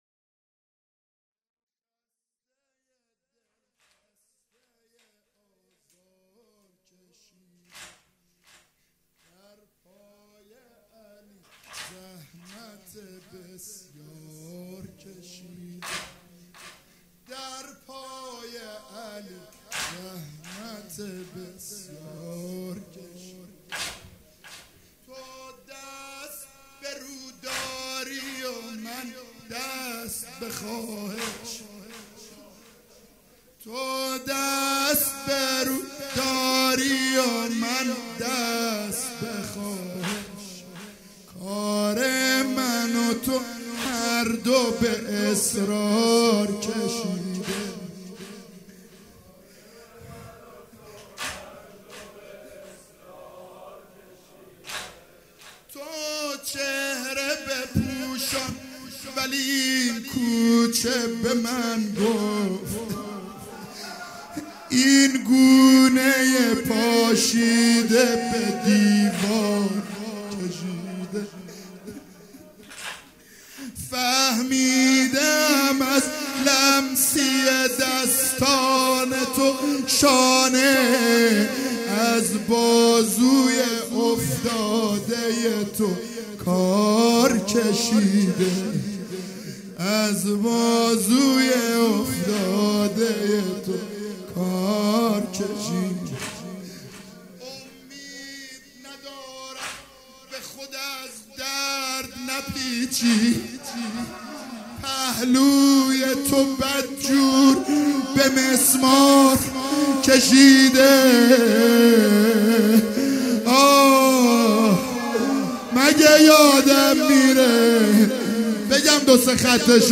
شب چهارم فاطمیه دوم-واحد